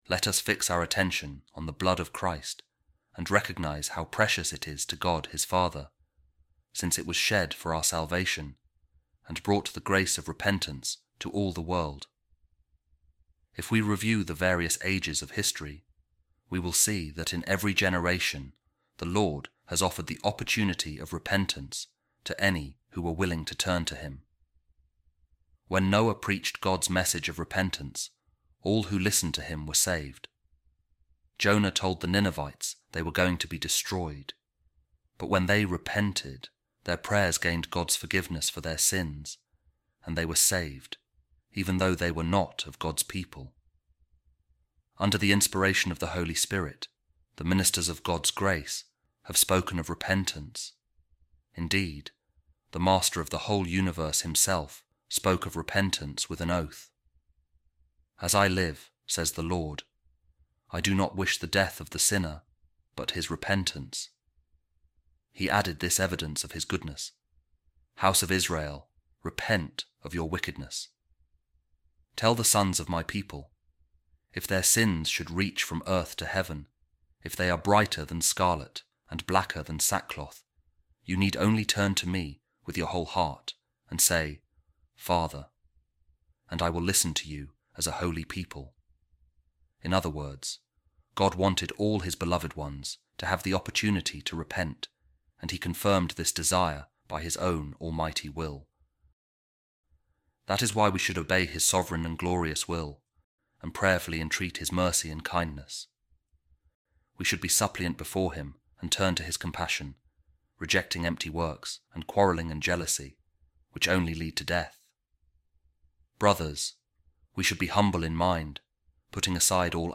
A Reading From The Letter Of Pope Saint Clement I To The Corinthians